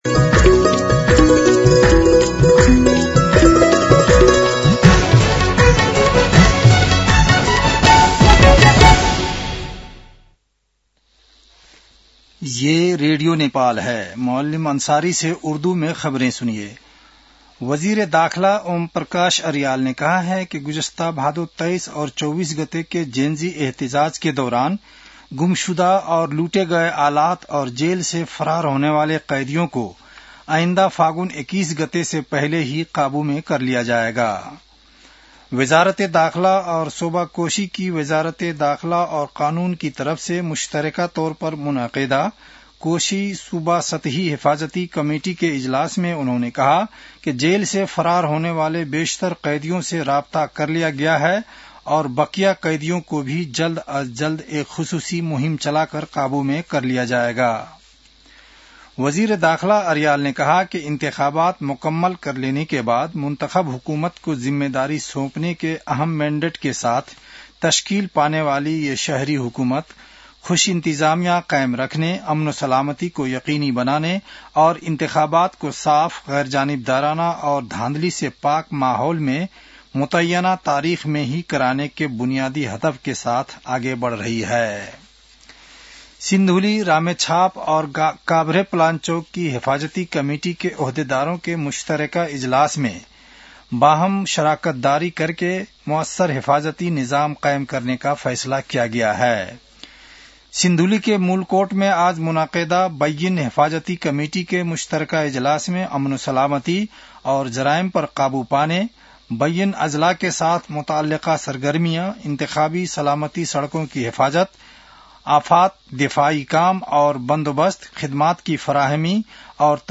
उर्दु भाषामा समाचार : १६ पुष , २०८२